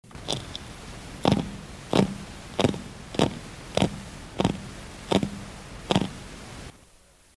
Sounds Made by Haemulon melanurum
Sound produced yes, active sound production
Type of sound produced escape sounds, grunts
Sound production organ pharyngeal teeth & swim bladder (similar to Haemulon album)
Sound mechanism pharyngeal teeth stridulation amplified by adjacent swim bladder (similar to Haemulon album)
Behavioural context under duress (manual & electric stimulation)